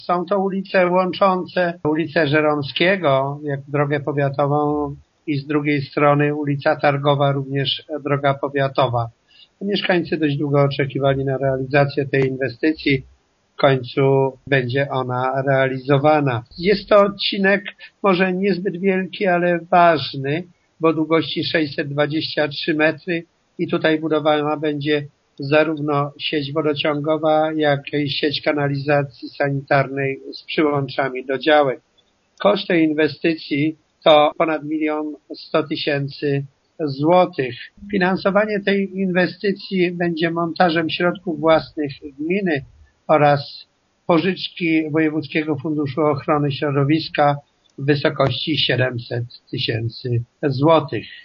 „Miasto właśnie podpisało umowę z wykonawcą robót, które będą prowadzone przy ul. Szkolnej i Bulwarze Targowym” – informuje zastępca burmistrza Włodzimierz Kabus: